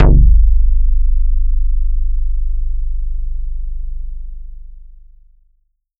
BERLIN BASS.wav